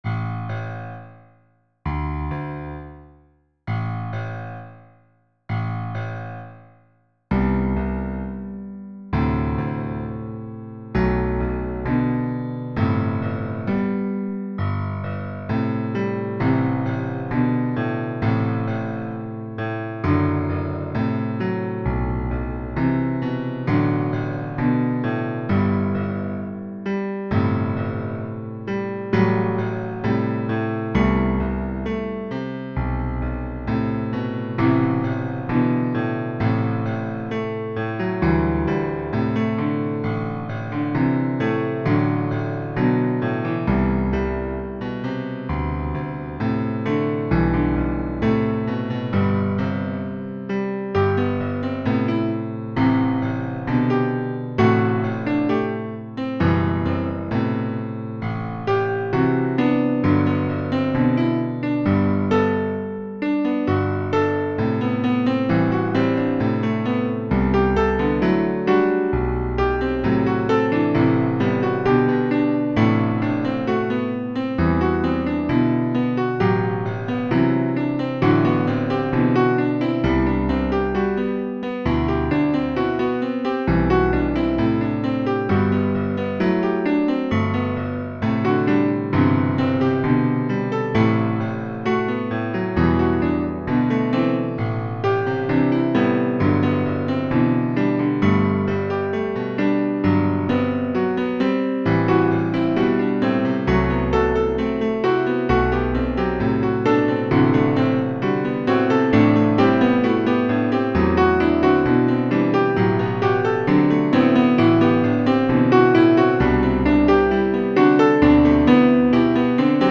Voici donc la première partie d'une nouvelle playlist "Piano".
Ce n'est pas du Chopin, ce n'est qu'un logiciel qui apprend à jouer du piano.
Hopalong ( 0.23 ) Sol (G) Penta 105